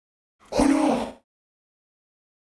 “Oh no” robot 2
Category 🗣 Voices
expression no oh robot voice word sound effect free sound royalty free Voices